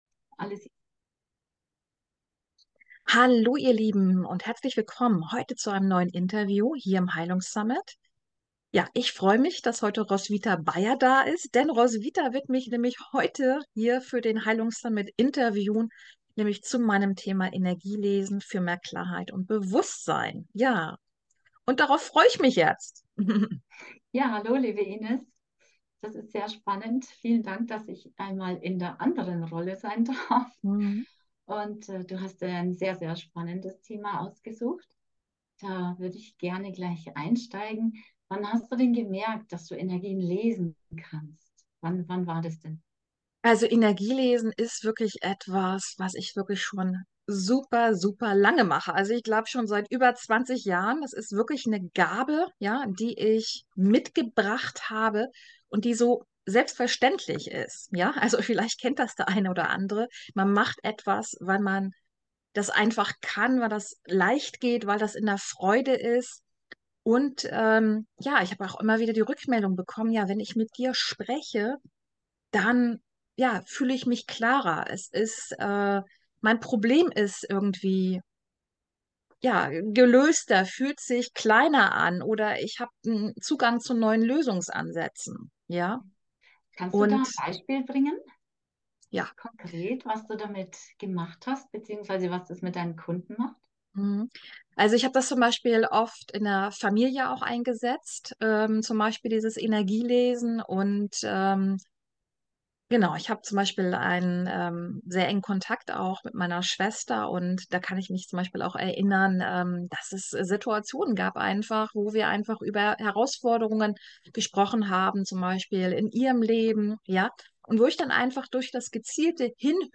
In dem Interview bekommst du einen Einblick was Energie lesen ist und welche unbewussten Themen dadurch sichtbar gemacht werden können. Dadurch wird der eigene Transformationsprozess beschleunigt.